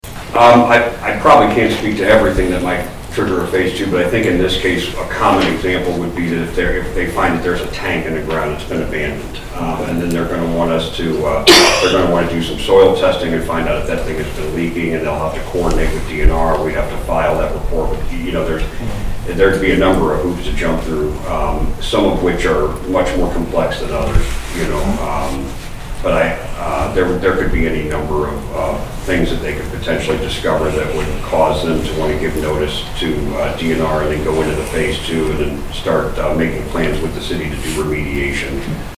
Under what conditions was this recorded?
The Marshall City Council approved a phase one environmental study for the old Fitzgibbon Hospital property during its meeting on Tuesday, June 6, 2023.